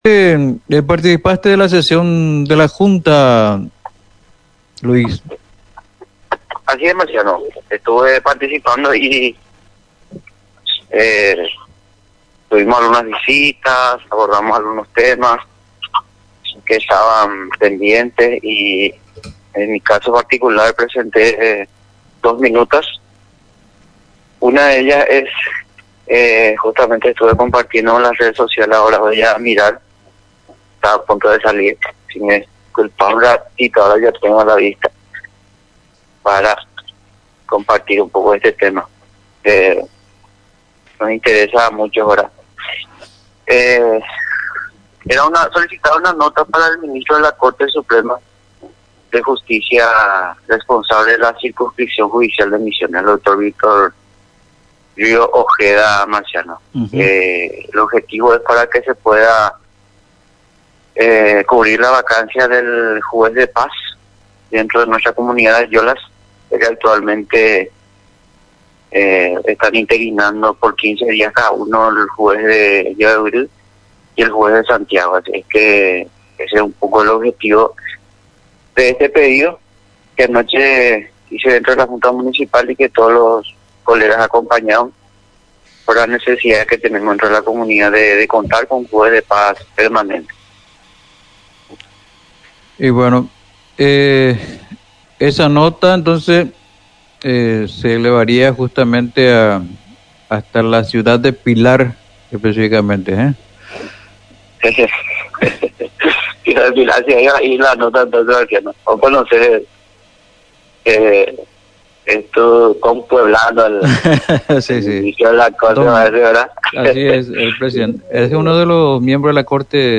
En diálogo con La mañana informativa, Luis Vera, concejal municipal, habló sobre la minuta que presentó en la ultima sesión de la Junta Municipal de Ayolas.